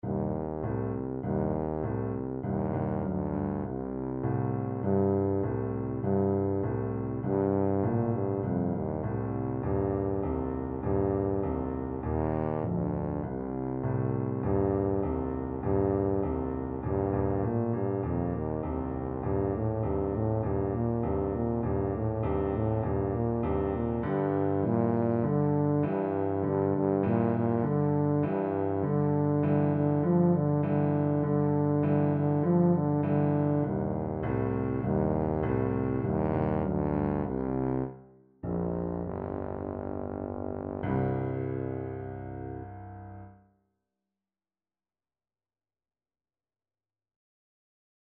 Stomping
4/4 (View more 4/4 Music)
Jazz (View more Jazz Tuba Music)